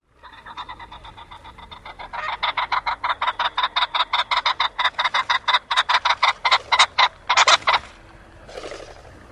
环颈雉飞鸣 雉鸡叫声